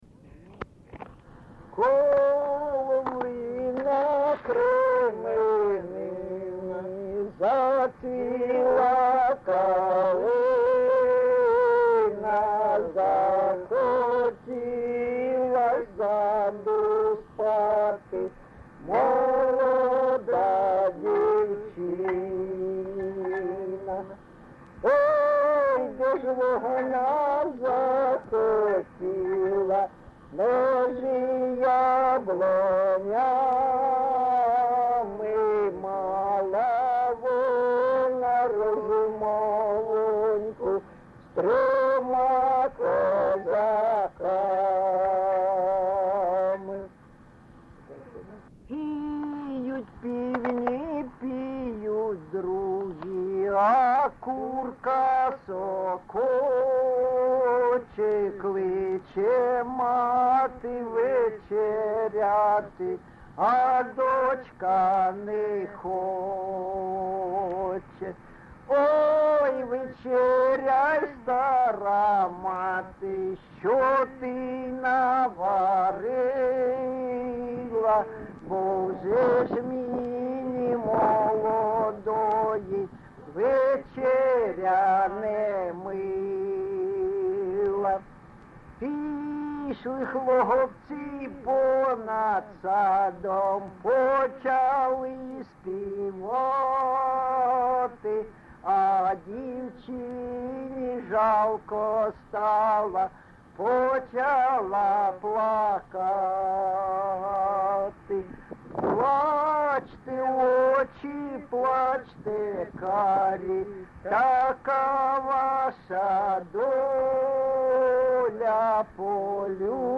ЖанрПісні з особистого та родинного життя
Місце записум. Дебальцеве, Горлівський район, Донецька обл., Україна, Слобожанщина